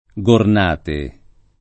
[ g orn # te ]